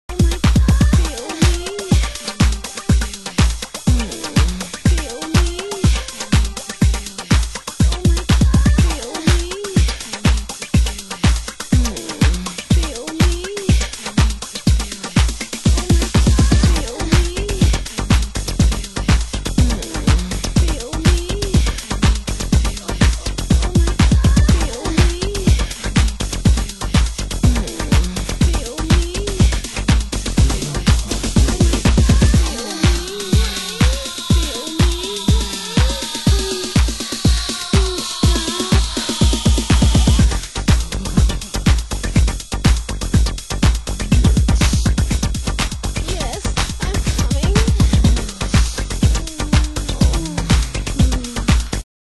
★90'S HOUSE CHICAGO